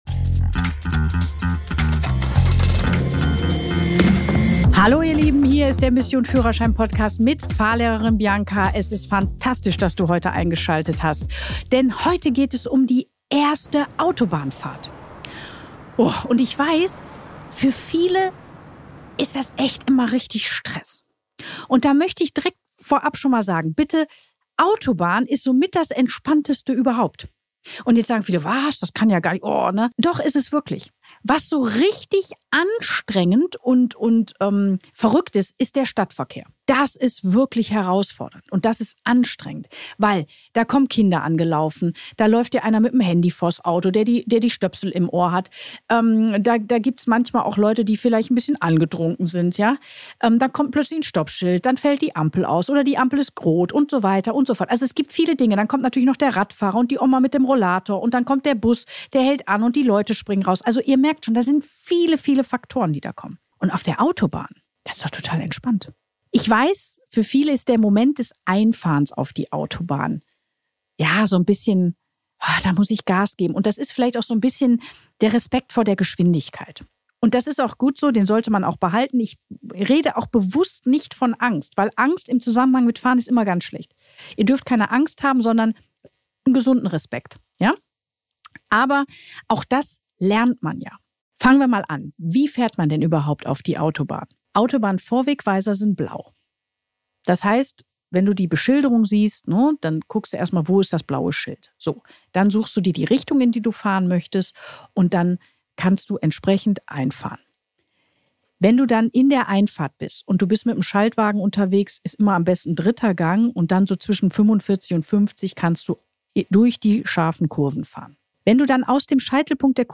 In dieser Solo-Folge vom Mission Führerschein Podcast nehme ich dich mit auf deine erste Autobahnfahrt – und zeige dir, warum sie viel entspannter ist, als du denkst.